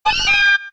Cri de Miaouss K.O. dans Pokémon X et Y.